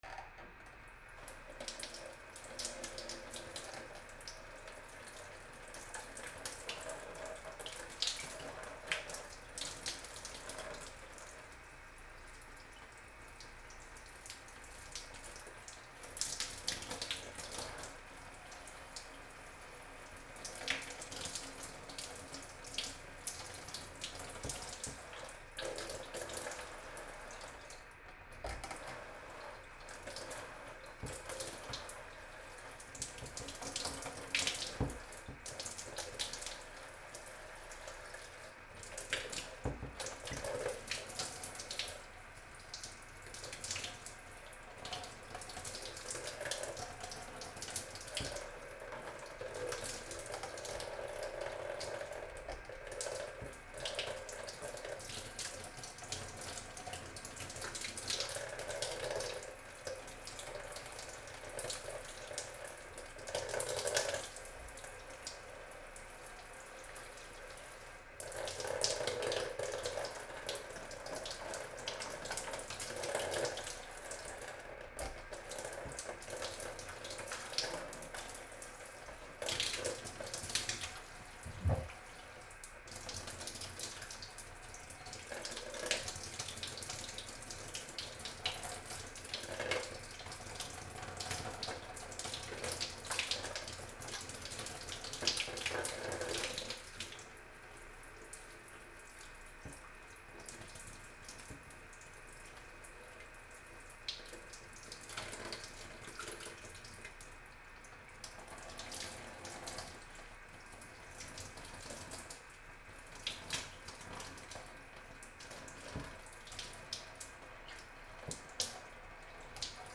/ M｜他分類 / L05 ｜家具・収納・設備 / お風呂
シャワールーム 小さい水の流れ